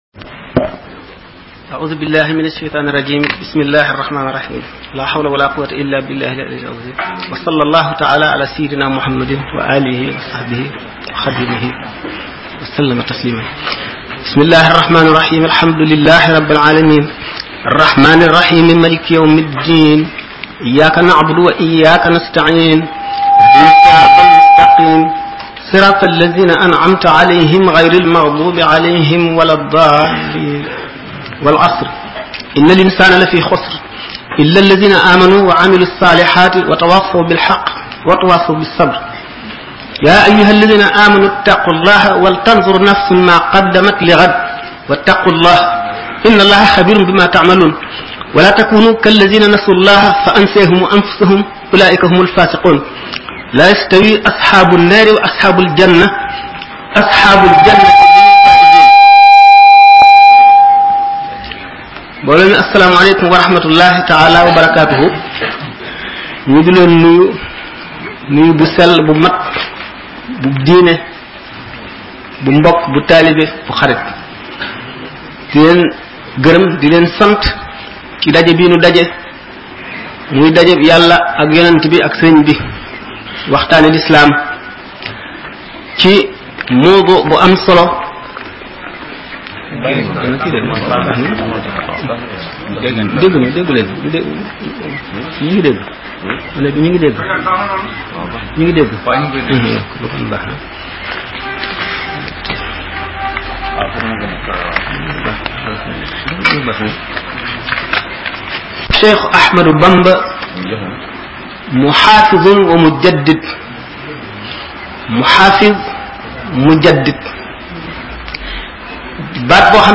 Conférences